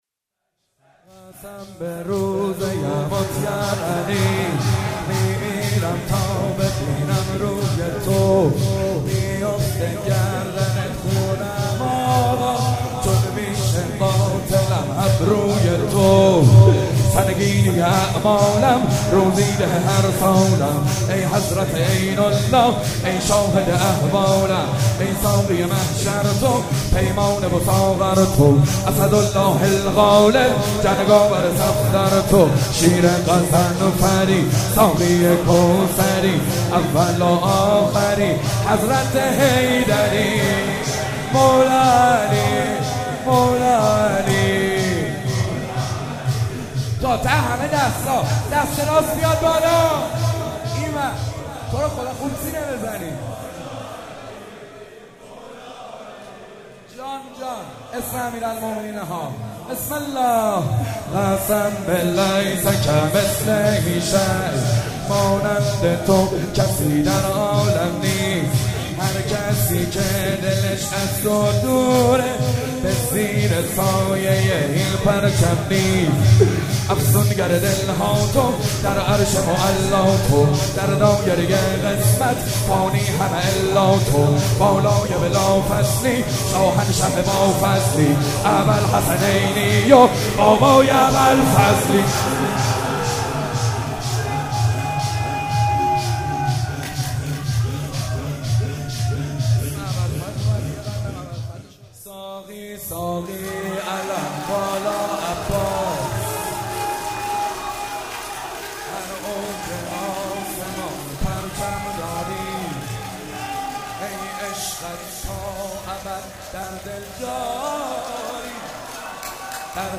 شهادت حضرت معصومه(س)/هیئت مکتب الزهرا(س)